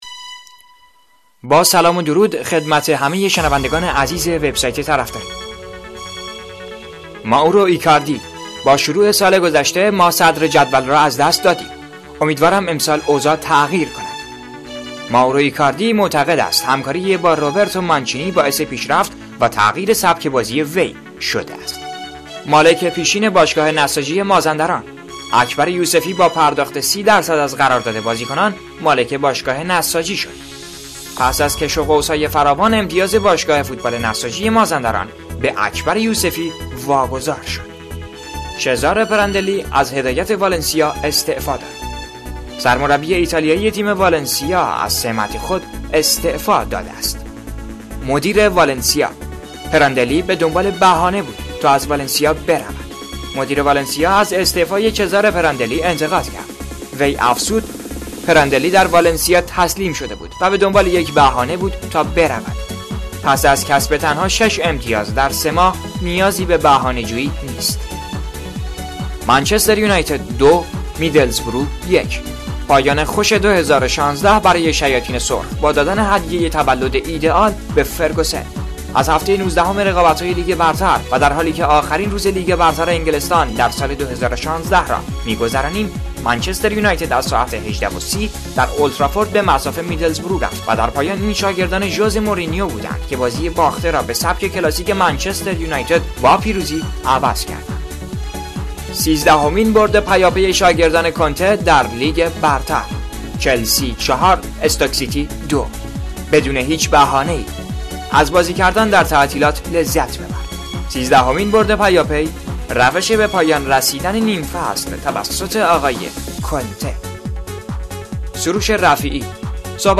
اخبار صوتی طرفداری با چاشنی طنز (11دی 1395)؛ از نتایج لیگ برتر انگلستان تا اظهارات پت نوین
طرفداری- مهم‌ترین اخبار روز فوتبالی جهان با چاشنی طنز و شوخی با خبرها در اخبار صوتی شبانگاهی طرفداری بشنوید.